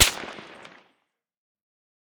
med_crack_05.ogg